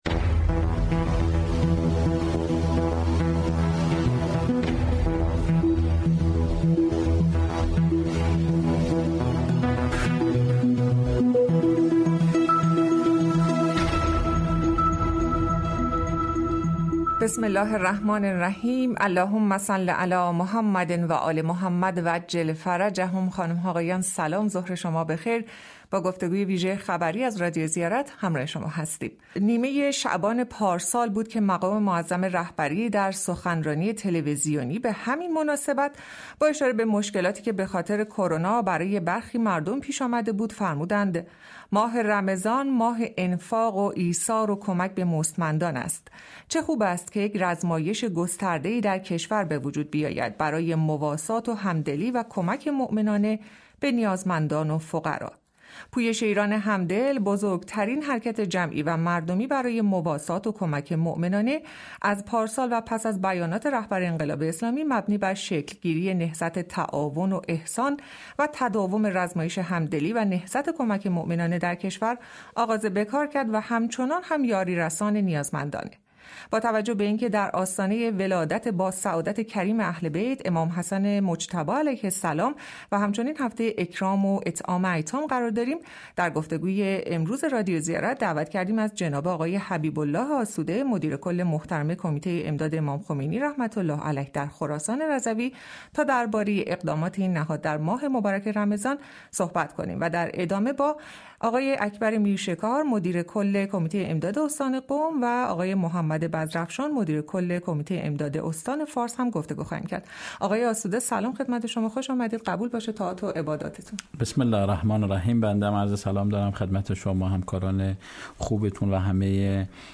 گفتگوی ویژه خبری